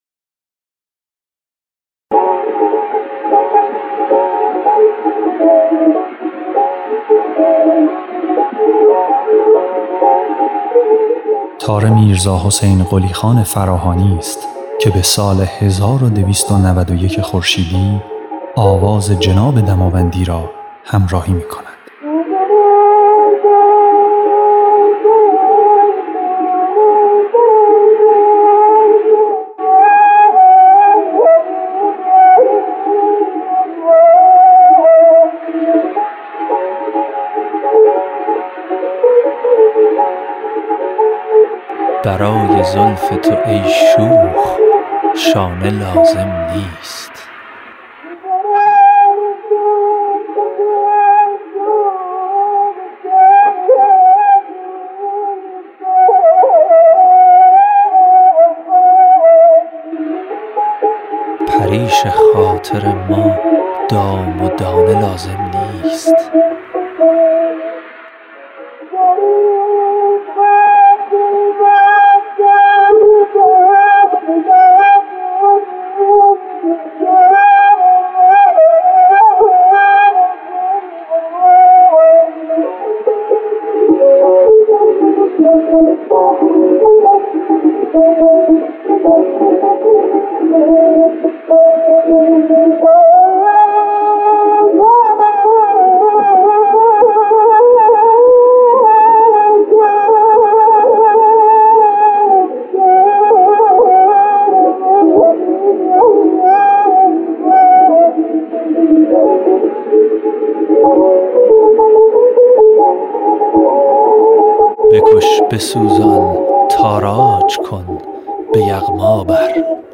خواننده
نوازنده تار